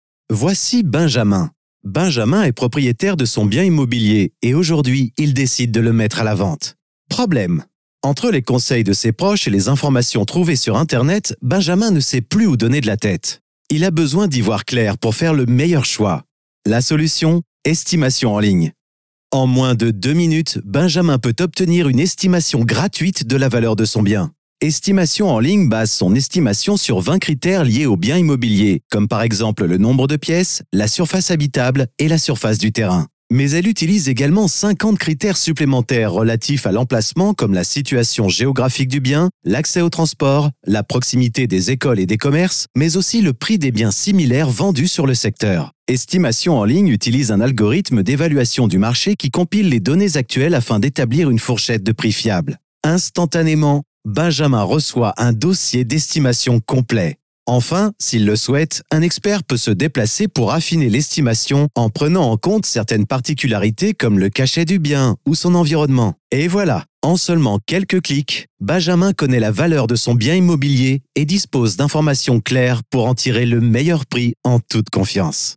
Naturelle, Enjouée, Polyvalente, Mature, Amicale
Corporate
He will record for you any kind of French voice over in a state of the art dubbing and recording studio.